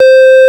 FARFISA4  C4.wav